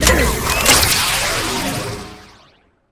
laser1.ogg